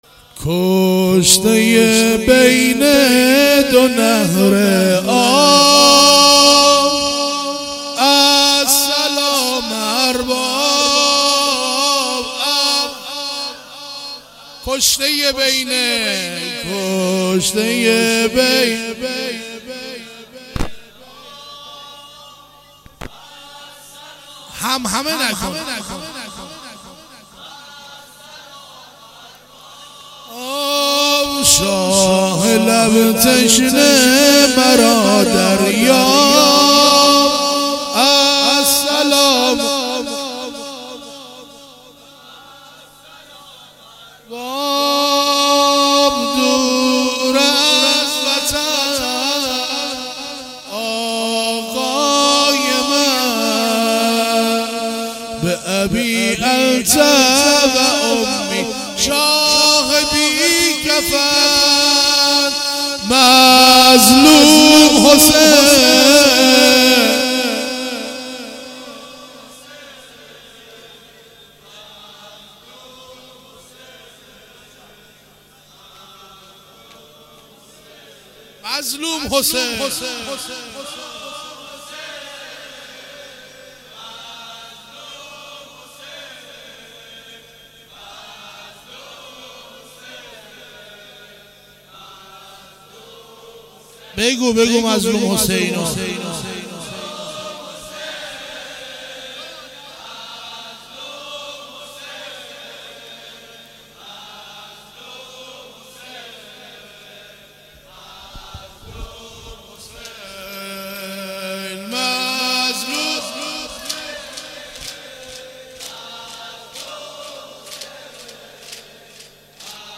زمینه شب چهارم محرم 96